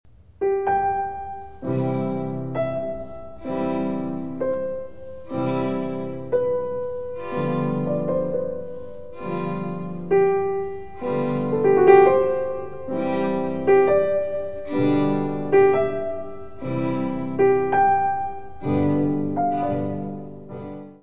: stereo; 12 cm + folheto
violino
Music Category/Genre:  Classical Music
Sonatas for violin and piano